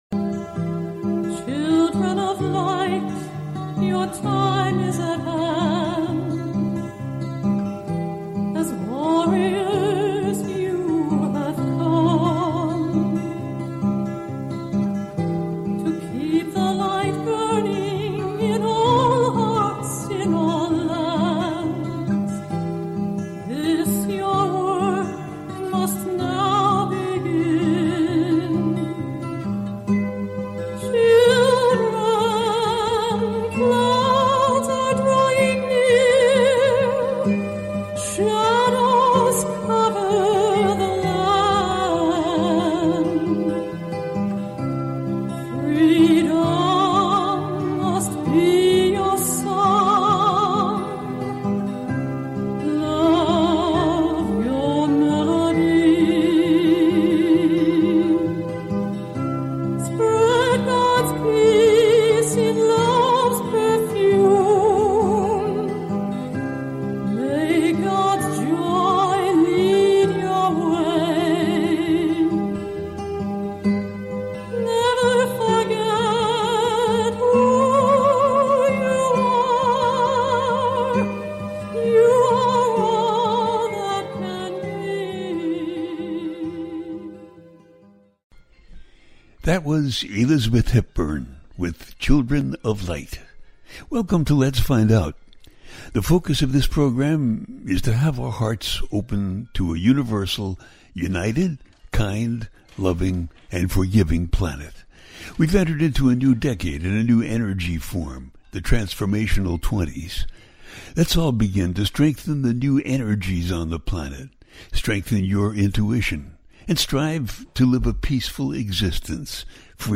The Upliftment Of 2025 - A teaching show